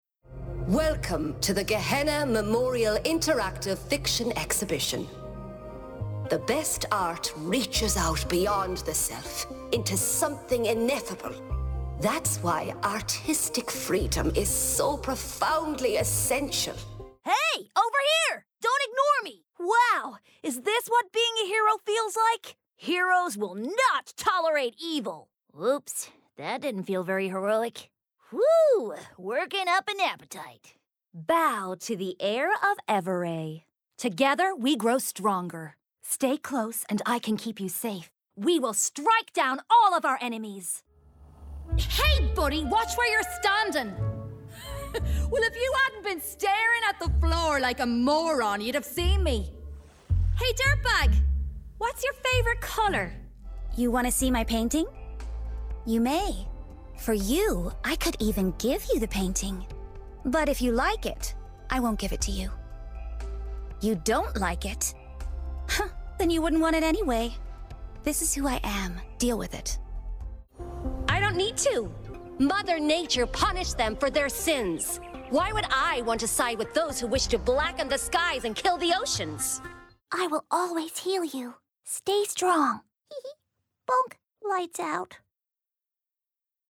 Gaming
Irish, American, Standard English/RP, London/Cockney, Northern (English), French
Actors/Actresses, Character/Animation, Comedy, Corporate/Informative, Husky/Seductive/Sexy, Modern/Youthful/Contemporary, Natural/Fresh, Quirky/Interesting/Unique, Smooth/Soft-Sell, Understated/Low Key, Upbeat/Energy